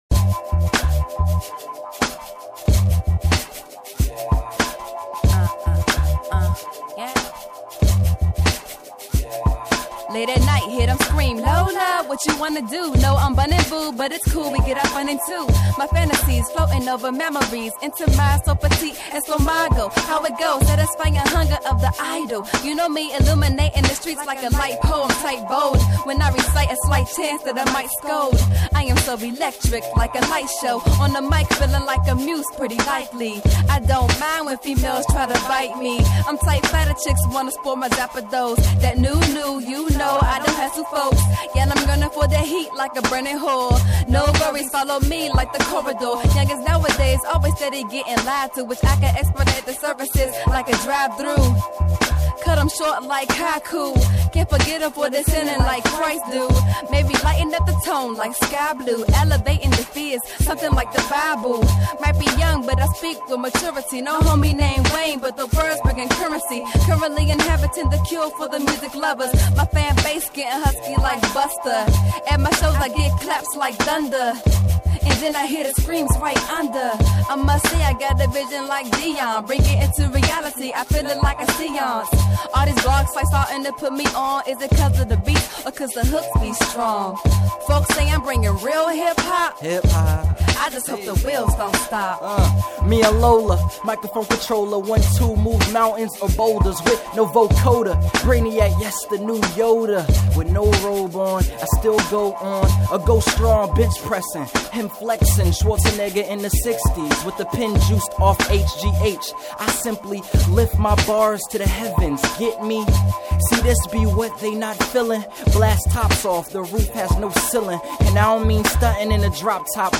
freestyle
Hip Hop